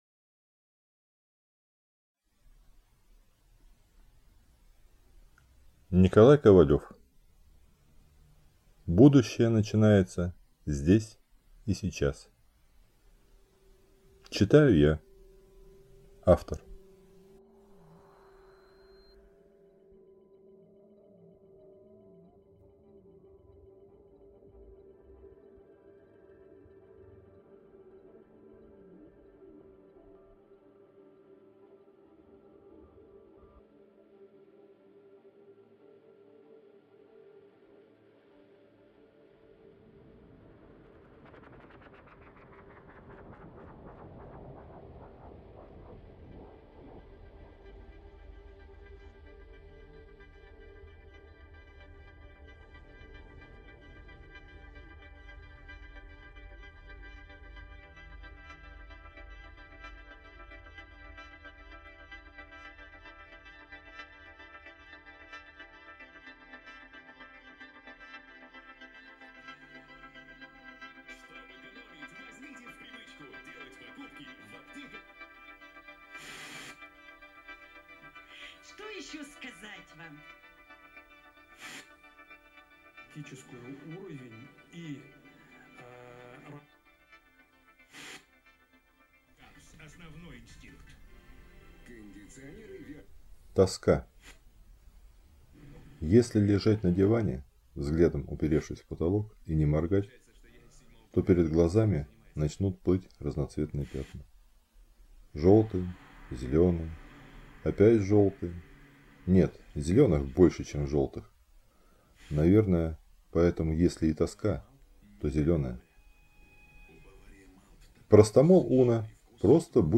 Аудиокнига БНЗиС | Библиотека аудиокниг